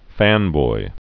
(fănboi)